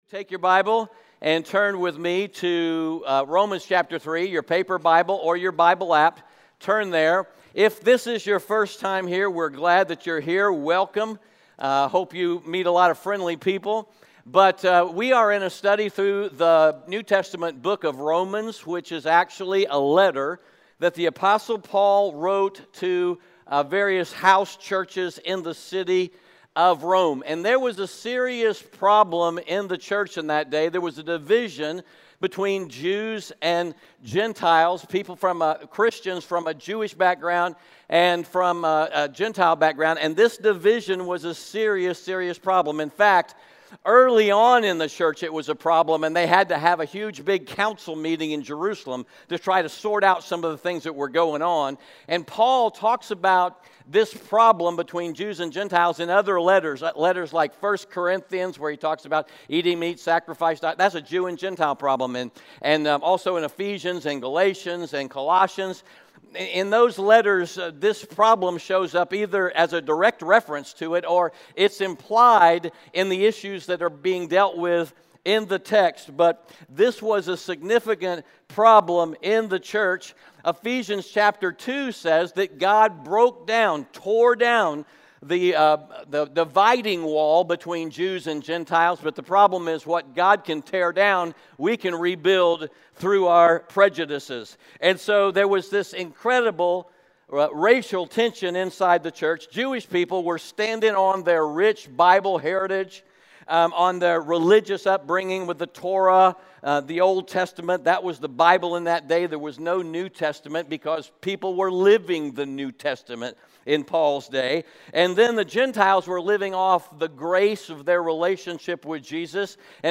*We are a church located in Greenville, South Carolina.